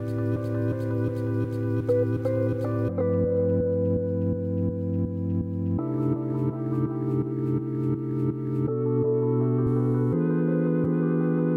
平滑的罗兹环路
描述：老罗德的感觉
Tag: 83 bpm Soul Loops Piano Loops 1.95 MB wav Key : Unknown